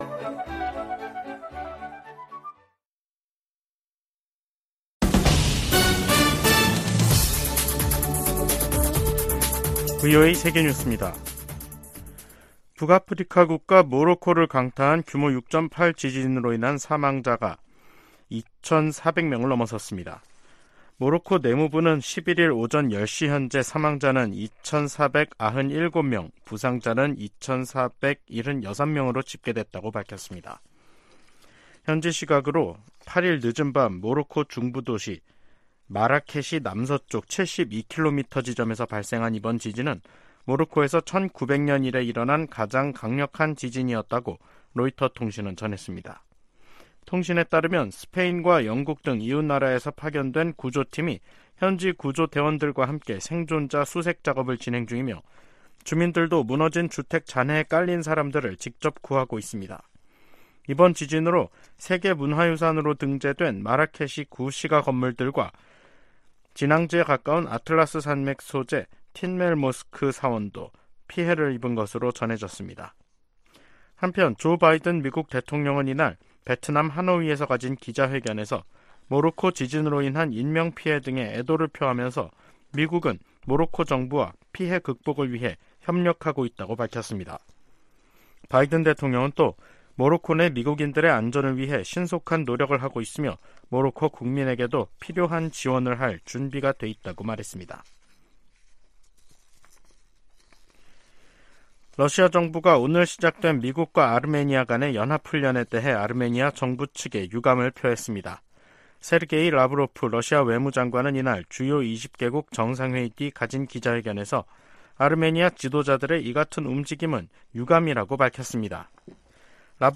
VOA 한국어 간판 뉴스 프로그램 '뉴스 투데이', 2023년 9월 11일 2부 방송입니다. 김정은 북한 국무위원장이 탑승한 것으로 보이는 열차가 러시아로 출발한 것으로 한국 언론들이 보도했습니다. 미국은 러시아와 북한간 무기 거래 저지를 위해 다양한 노력을 기울이면서 상황을 계속 주시하고 있다고 백악관 고위 관리가 밝혔습니다.